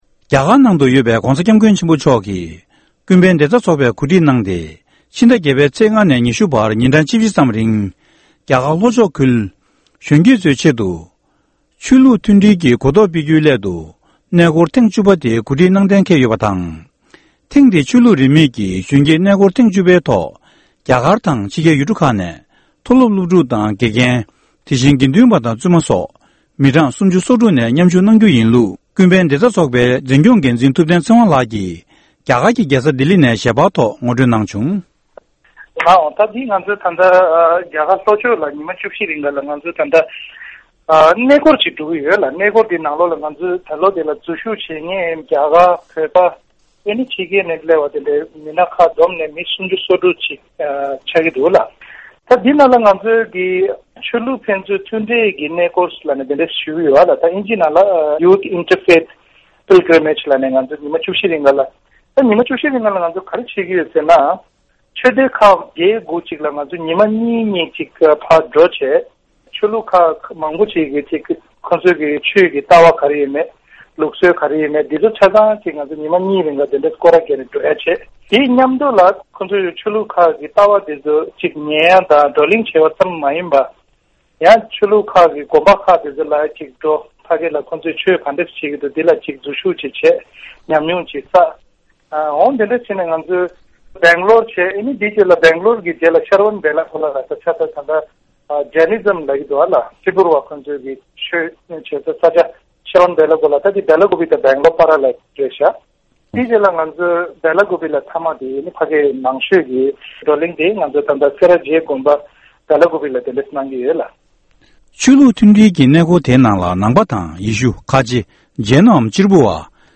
སྒྲ་ལྡན་གསར་འགྱུར། སྒྲ་ཕབ་ལེན།
གནས་འདྲི